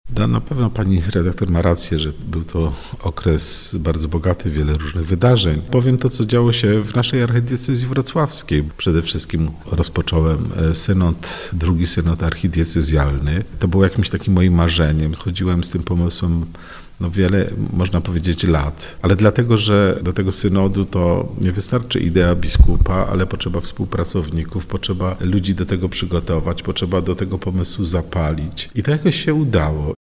– Nawet jeśli mogę być zadowolony, że udało mi się na jakimś odcinku zrobić krok do przodu, to dostrzegam, że nie jest zupełnie tak jakbym chciał. Trzeba się ciągle nawracać. Każde przystąpienie do sakramentu pokuty, każde wejście w swoje sumienie, pokazuje jak wiele jest jeszcze do zrobienia – przyznaje w rozmowie z Radiem Rodzina ksiądz arcybiskup Józef Kupny, Metropolita Wrocławski.